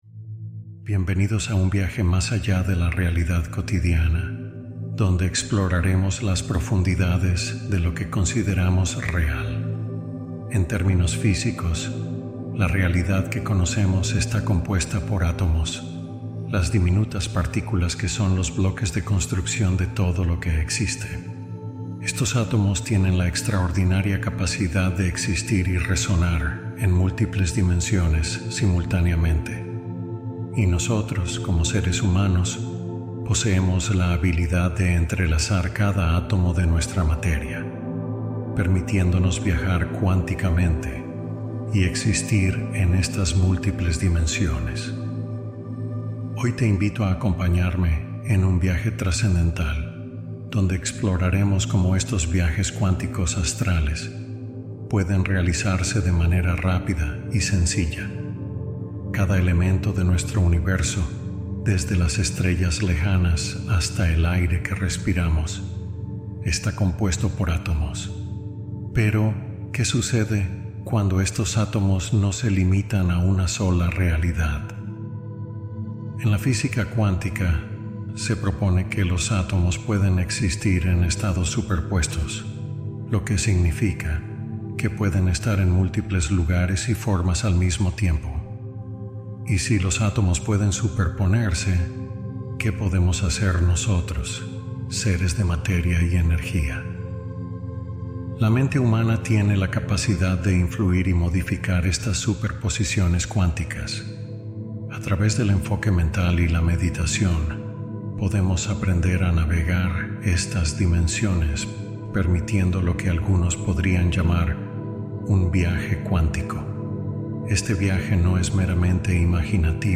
Viaje cuántico simbólico: meditación guiada de exploración